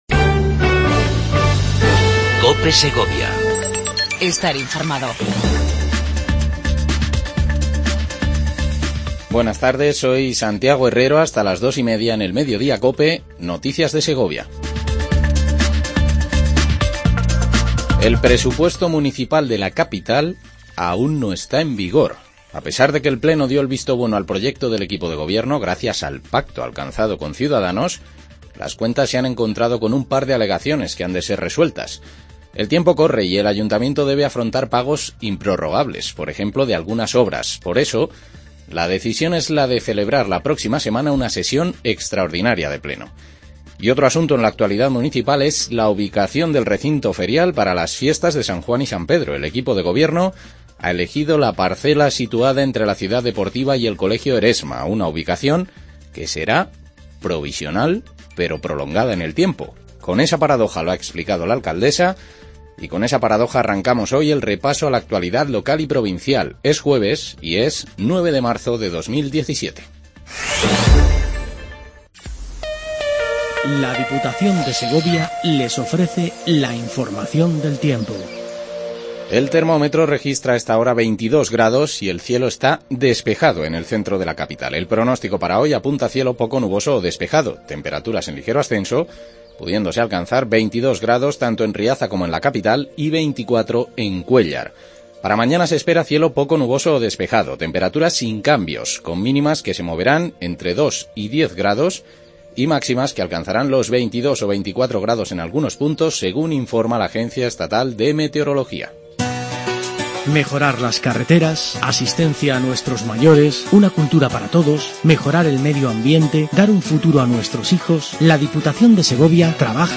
INFORMATIVO MEDIODIA COPE EN SEGOVIA